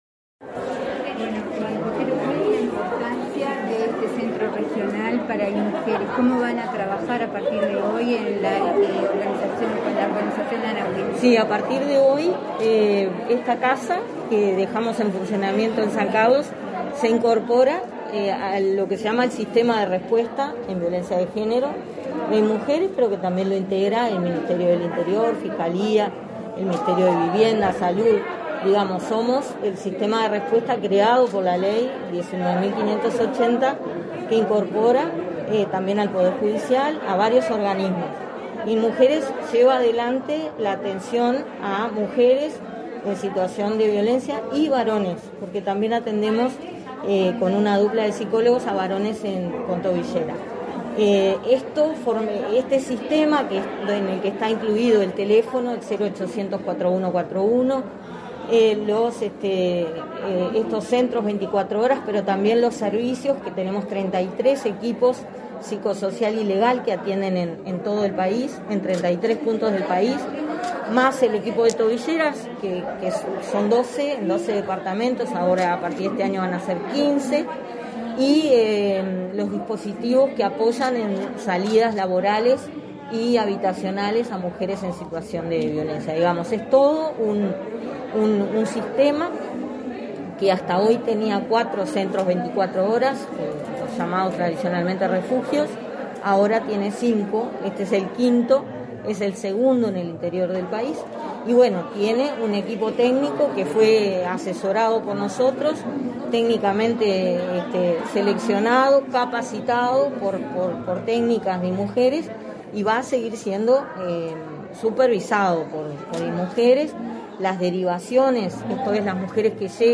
Declaraciones a la prensa de la directora del Inmujeres, Mónica Bottero